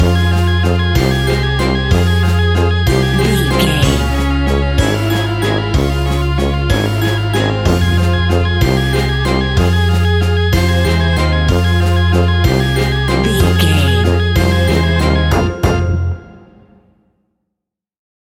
Aeolian/Minor
ominous
eerie
brass
organ
drums
synthesiser
strings
spooky
horror music